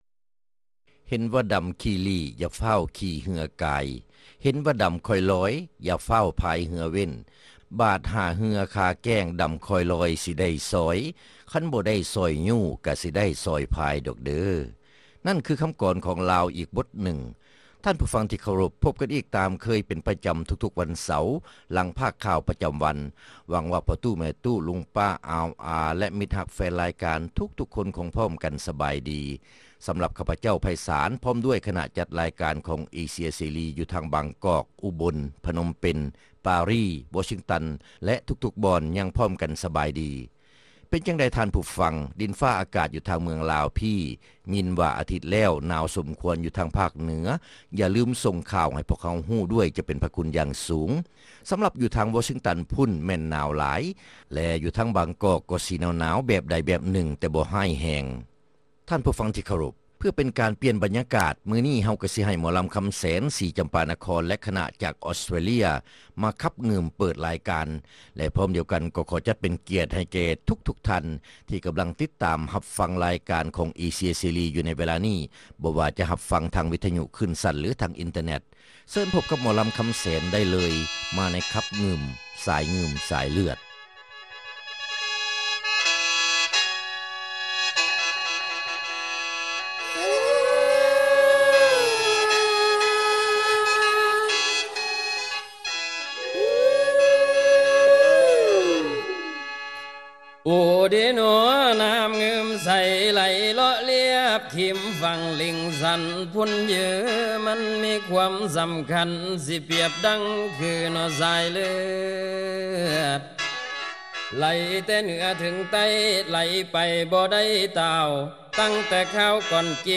ຣາຍການໜໍລຳ ປະຈຳສັປະດາ ວັນທີ 2 ເດືອນ ກຸມພາ ປີ 2007